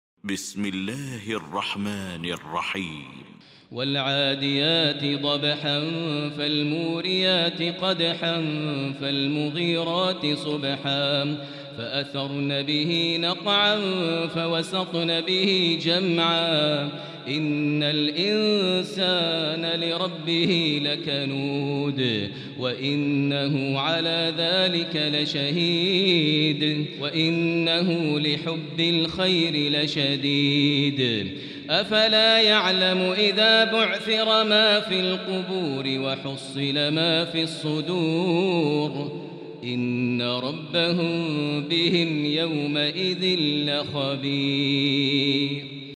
المكان: المسجد الحرام الشيخ: فضيلة الشيخ ماهر المعيقلي فضيلة الشيخ ماهر المعيقلي العاديات